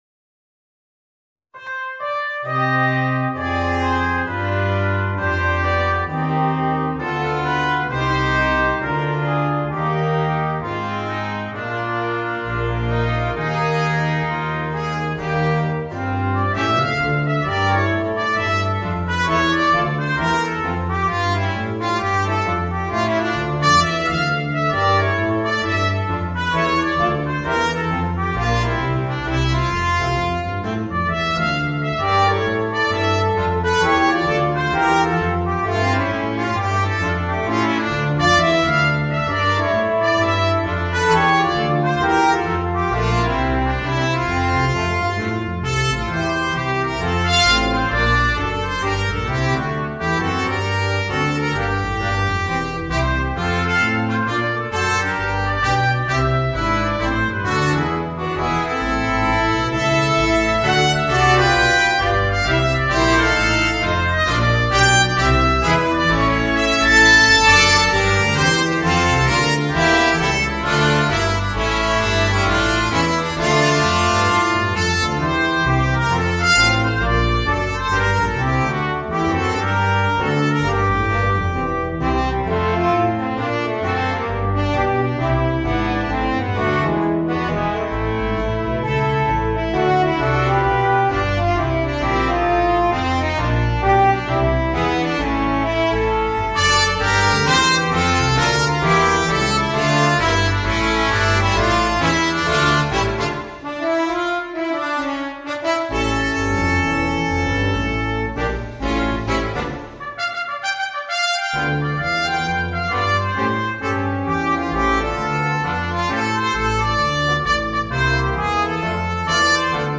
Voicing: 2 Trumpets, 2 Trombones and Drums